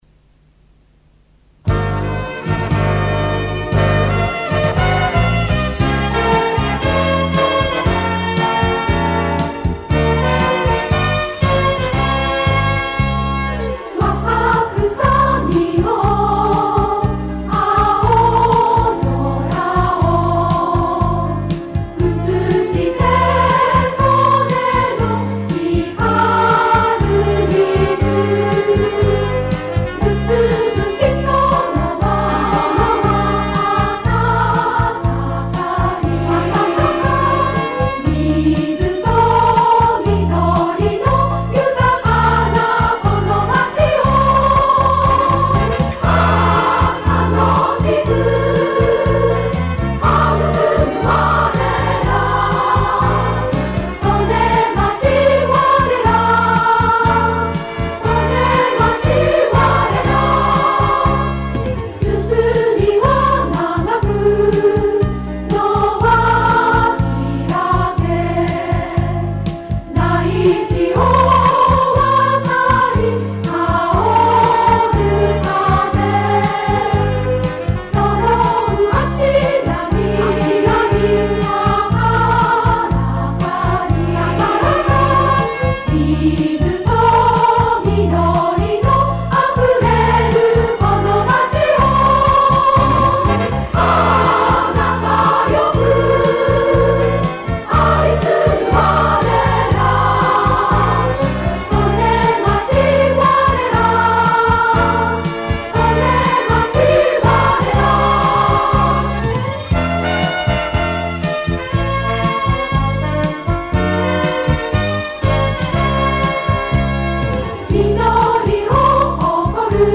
歌入り